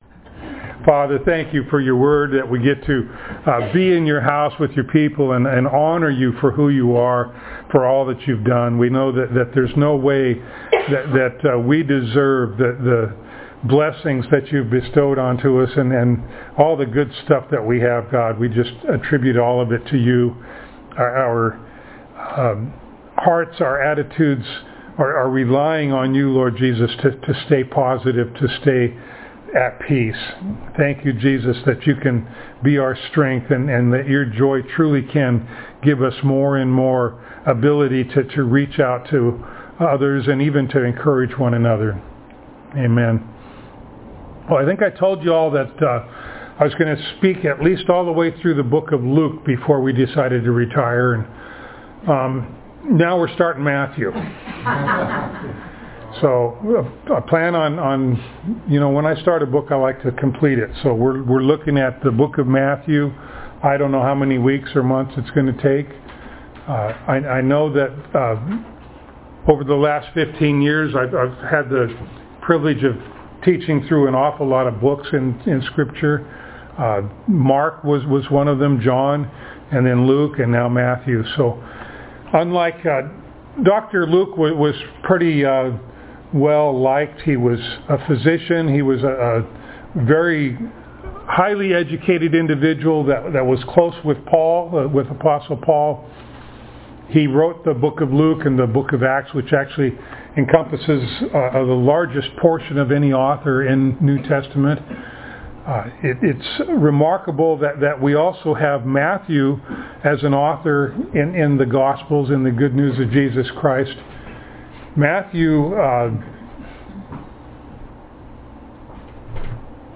Passage: Matthew 1:1-25 Service Type: Sunday Morning